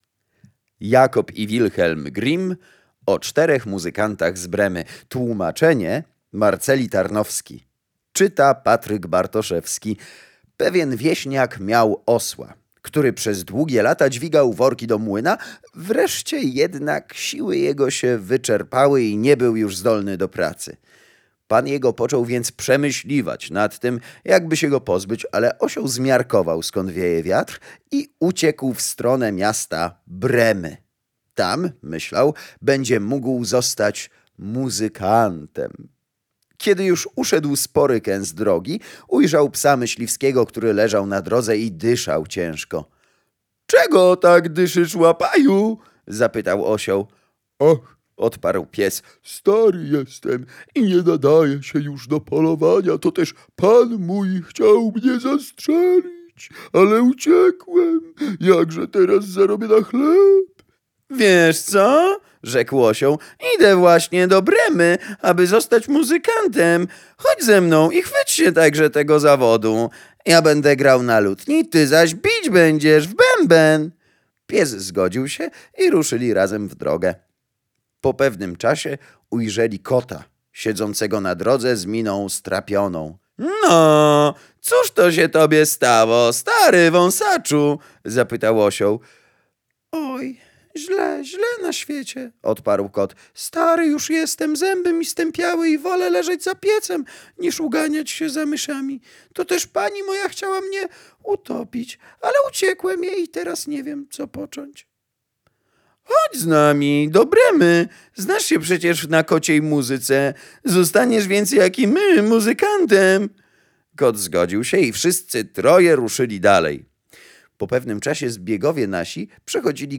A dziś postanowiłem przeczytać baśń braci Grimm.